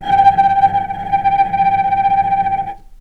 vc_trm-G5-pp.aif